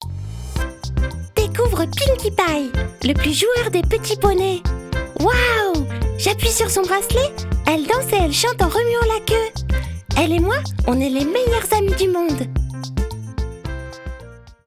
Enregistrements qualité studio
Voix enfantine
Fraîche et pétillante, idéale pour les projets créatifs et dynamiques
voix-petite-fille.wav